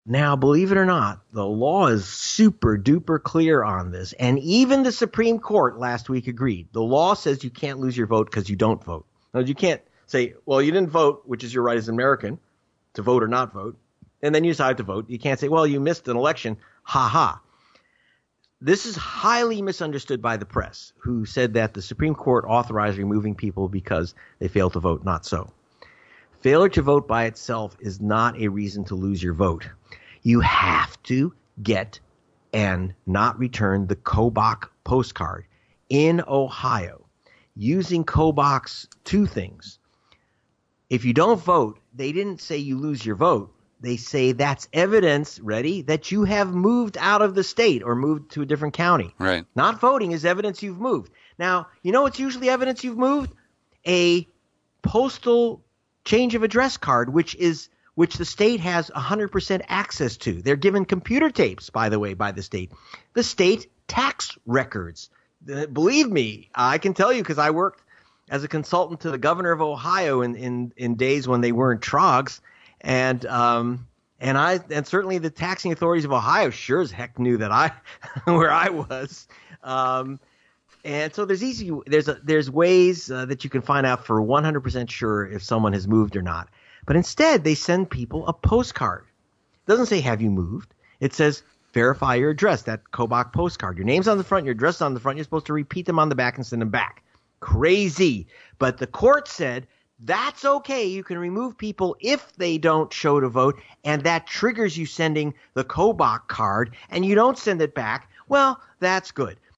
In-Depth Interview: Greg Palast Updates Kris Kobach’s Court Defeat, Supreme Court’s OK of Voter Caging